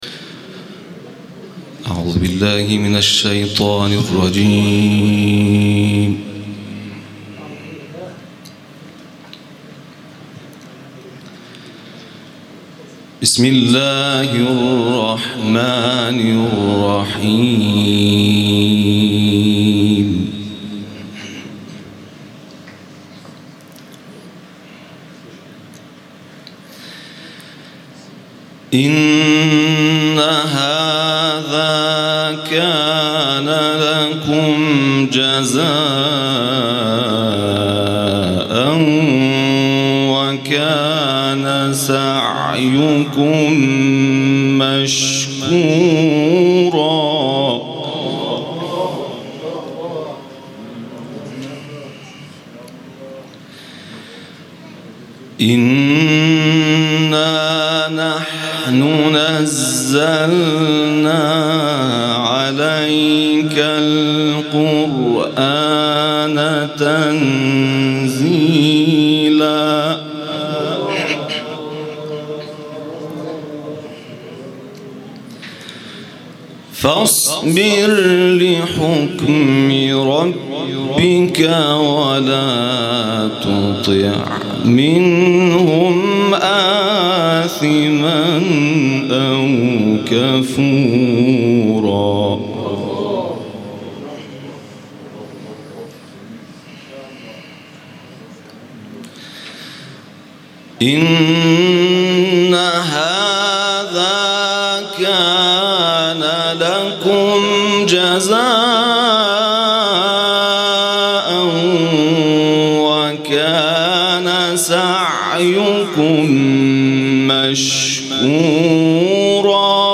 محفلی در طراز جهانی
در این محفل باشکوه انس با قرآن، قاریان یکی پس از دیگری به تلاوت پرداختند که در ادامه صوت آنها تقدیم می‌شود.